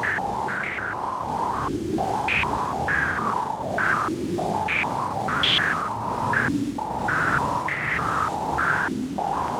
STK_MovingNoiseE-100_03.wav